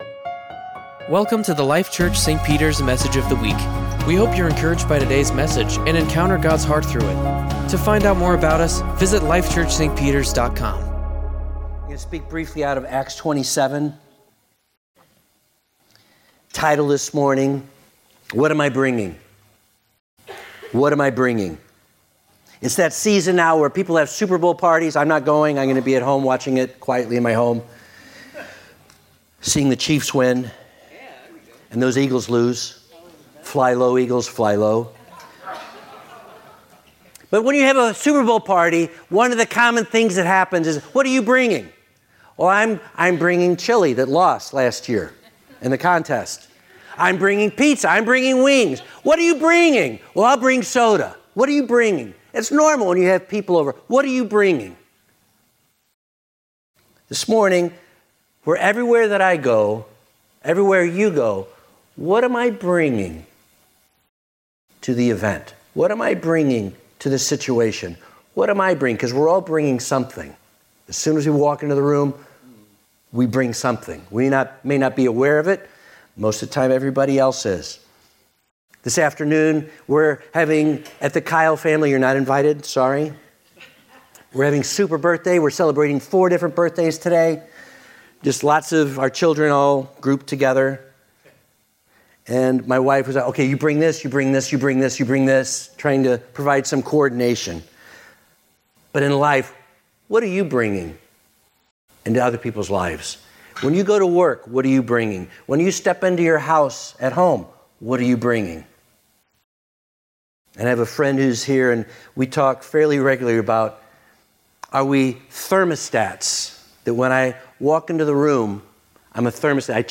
Sermons | Life Church - St. Peters